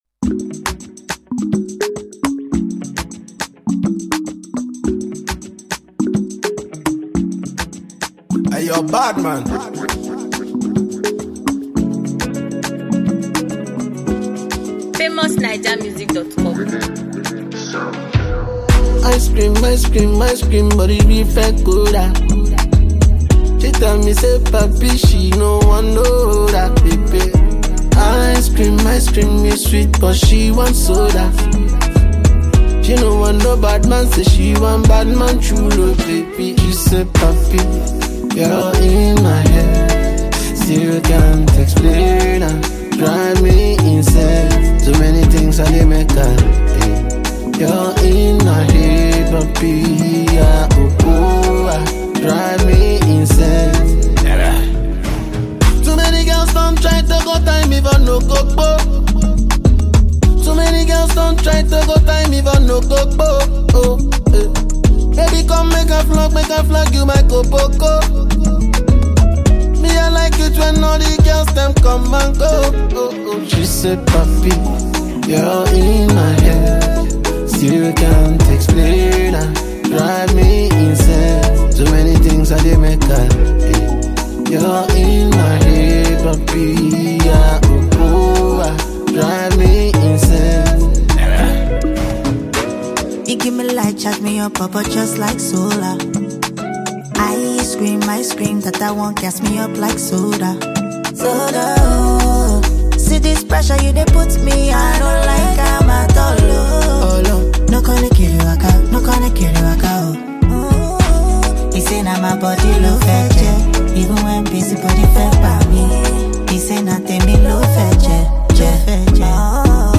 Nigerian Afrobeat Genius